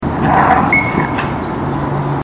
A wound was actually inflicted during this incident, as can be heard in the very short [and unfortunately muffled] recording — there’s a growl and then a yip of pain — and as can be seen in the slide below, taken the next day.